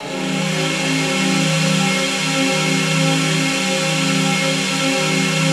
ATMOPAD11 -LR.wav